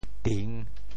镇（鎮） 部首拼音 部首 钅 总笔划 15 部外笔划 10 普通话 zhèn 潮州发音 潮州 ding3 文 中文解释 镇 <动> 压：～尺（用金属、玉石等制成的尺形文具，用来压书和纸。